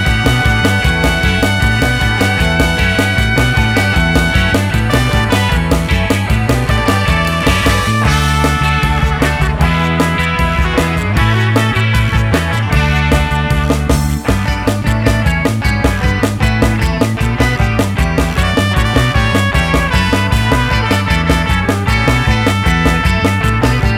No Backing Vocals Or Crowd FX Pop (1960s) 2:12 Buy £1.50